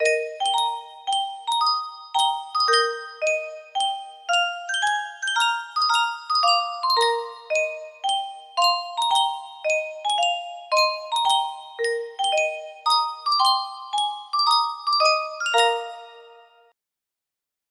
Yunsheng Music Box - Casta Diva Bellini 1053 music box melody
Full range 60